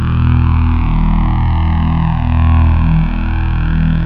saberIdle.wav